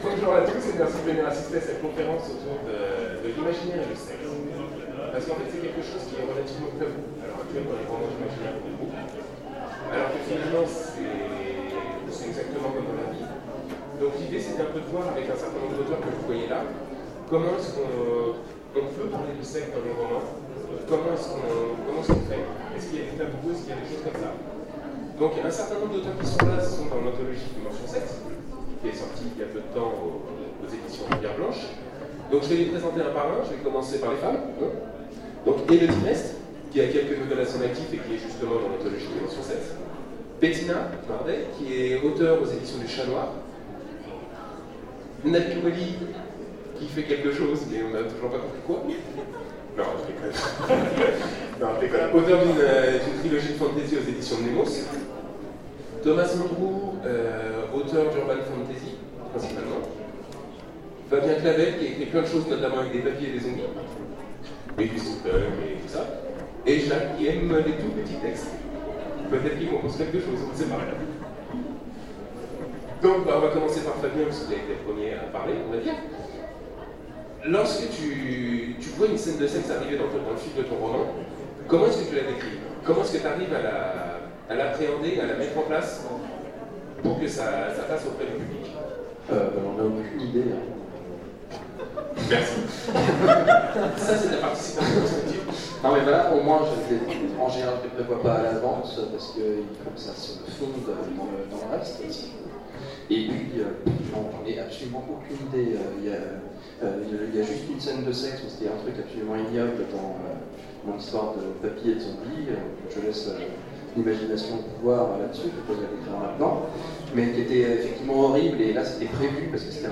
Salon fantastique 2016 : Conférence Le sexe et l’imaginaire